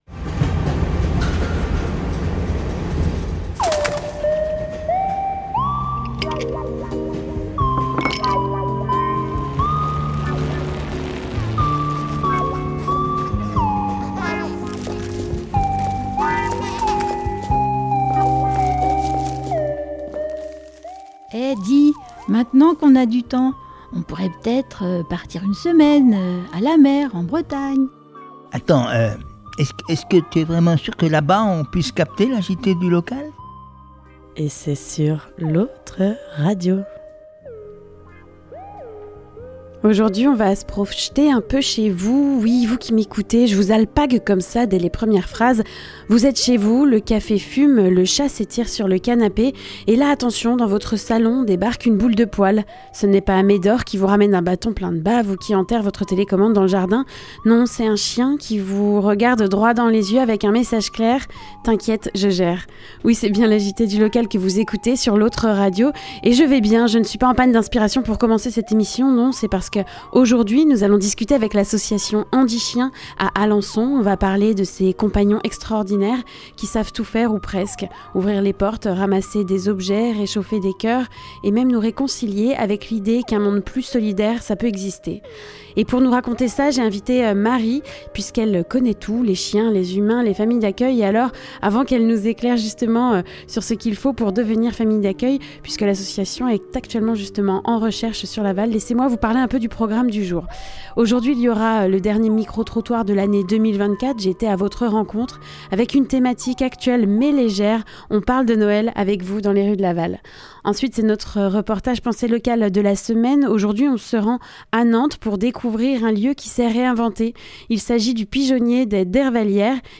Le reportage Penser Local : Le pigeonnier des Dervallières de Nantes, un lieu réinventé